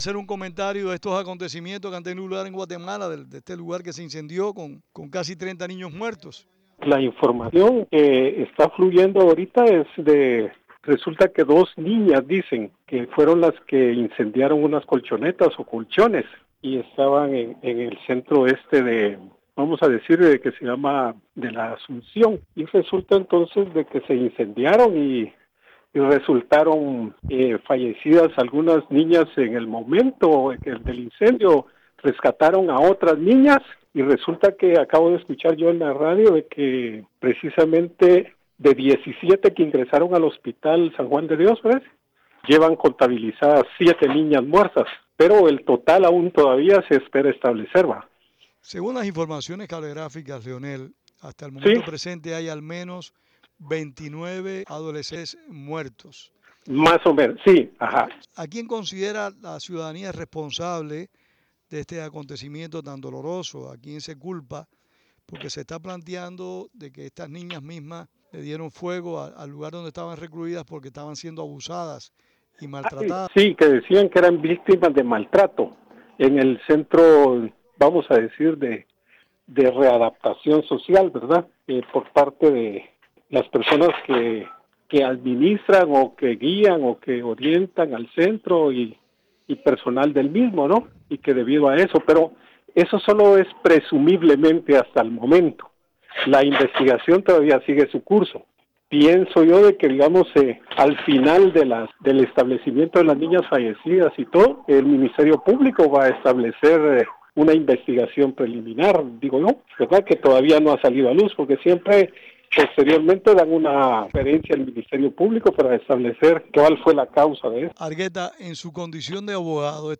Análisis en Radio Martí